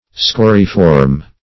Scoriform \Sco"ri*form\, a. In the form of scoria.